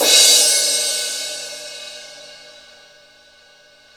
Index of /90_sSampleCDs/Roland L-CD701/CYM_Crashes 1/CYM_Crash menu